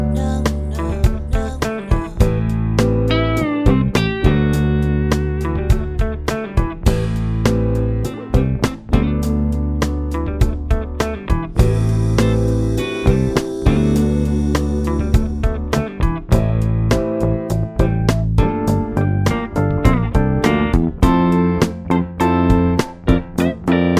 no sax solo Pop (1960s) 3:51 Buy £1.50